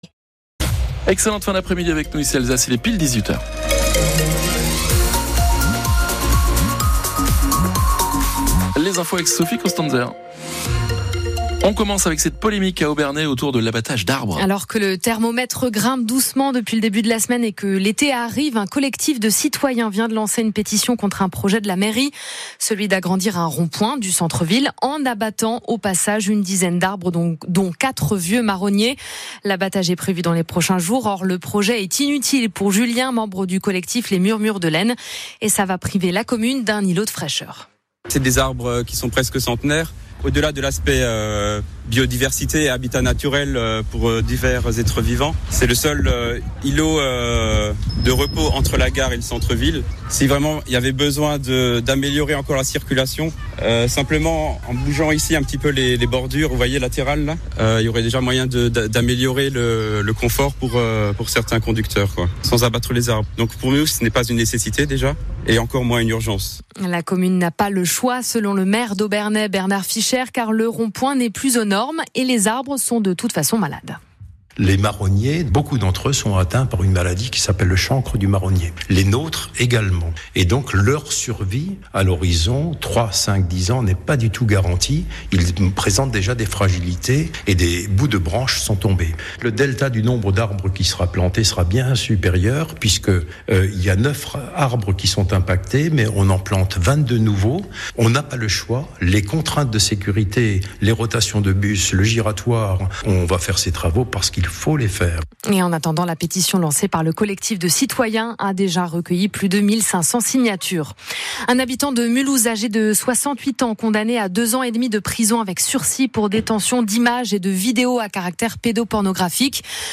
ICI ALSACE (Radio), 19.06.25 Obernai. Abattre ces arbres n’est ni une nécessité et encore moins une urgence.
Le journal de 6h (annonce) / Le journal de 7h / Le journal de 8h (annonce) / Le journal de 9h (fin du journal) /